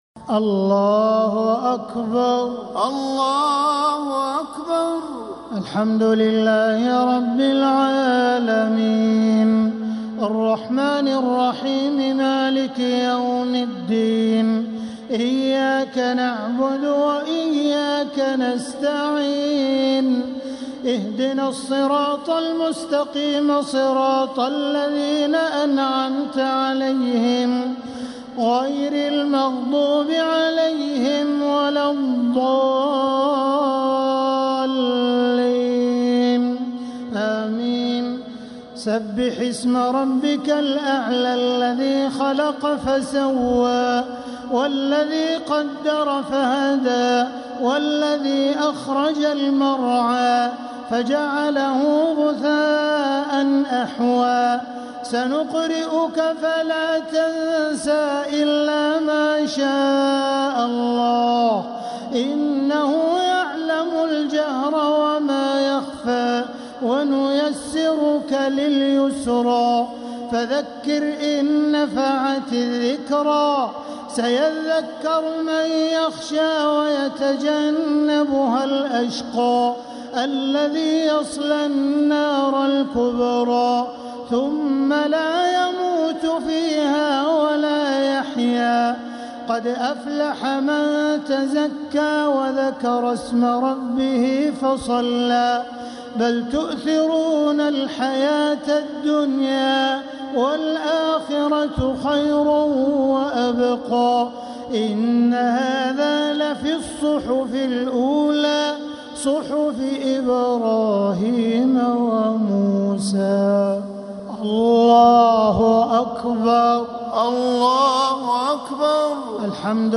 الشفع و الوتر ليلة 21 رمضان 1447هـ | Witr 21 st night Ramadan 1447H > تراويح الحرم المكي عام 1447 🕋 > التراويح - تلاوات الحرمين